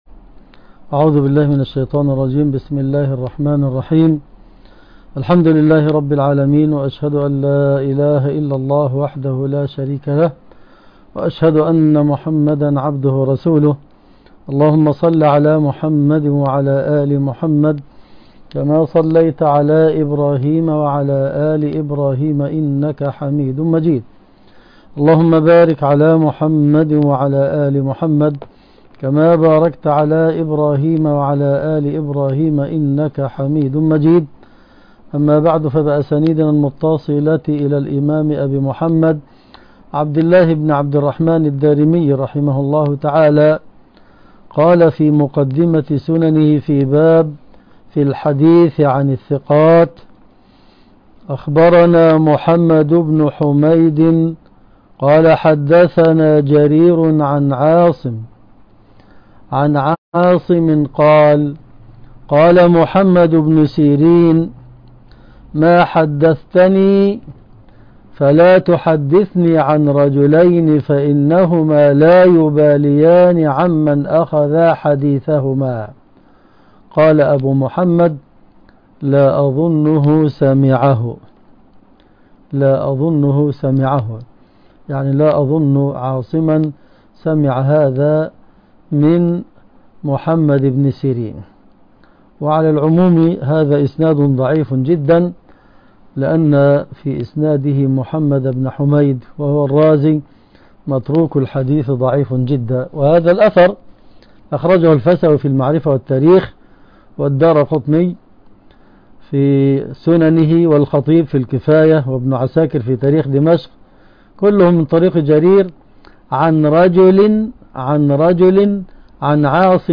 الدرس ( 31) شرح سنن الدارمي